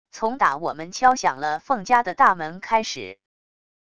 从打我们敲响了凤家的大门开始wav音频生成系统WAV Audio Player